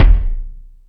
Lotsa Kicks(22).wav